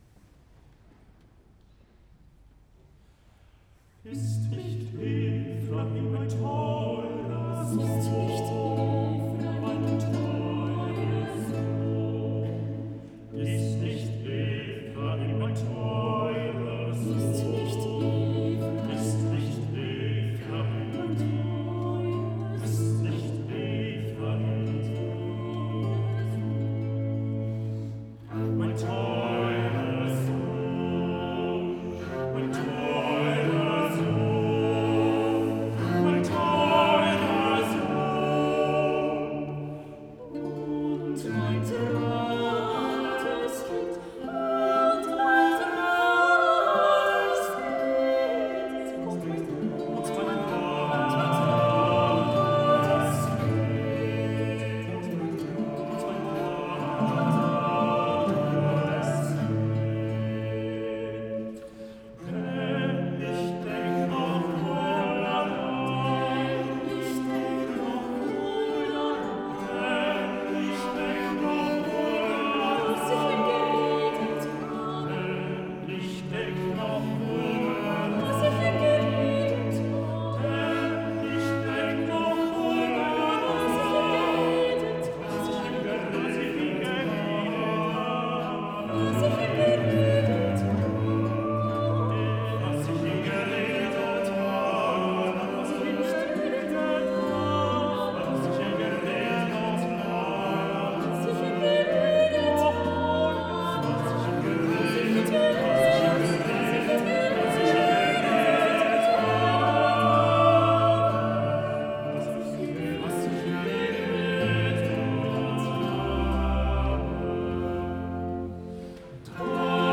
Avec l’autorisation de RTS-Espace 2, vous pouvez ici réécouter le concert des Fontaines d’Israël de Schein donné à la collégiale de St-Ursanne en 2018.